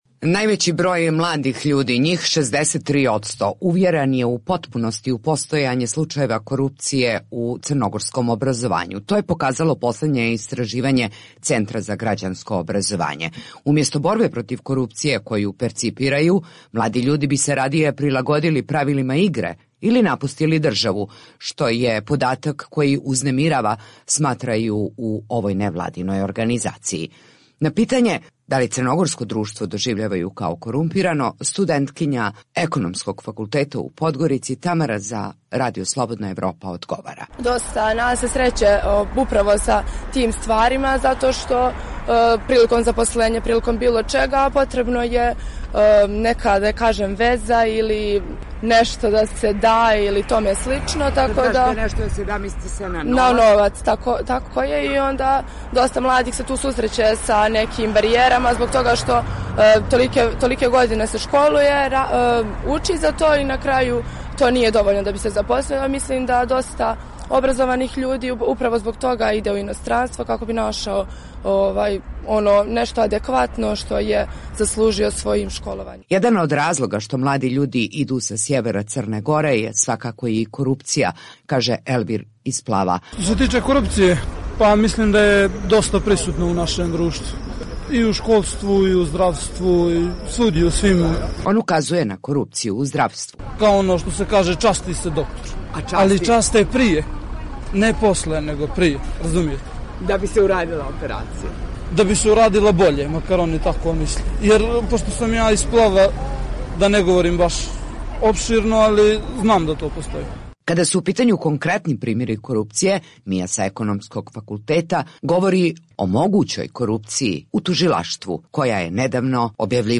U ovosedmičnoj emisiji "Glasom mladih" pitali smo mlade iz Srbije, Crne Gore i BiH da li i koliko korupcija utiče na kvalitet njihovog života, ali i ostaviravanje životnih ciljeva?